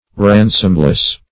Ransomless \Ran"som*less\, a. Incapable of being ransomed; without ransom.